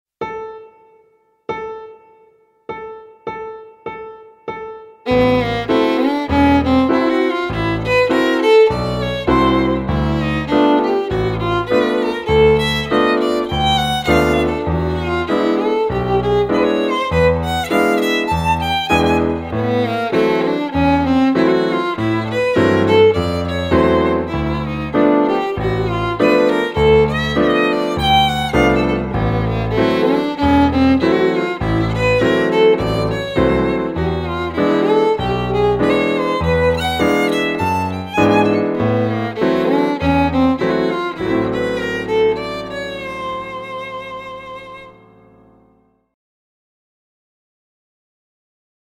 -Arpegios y acordes: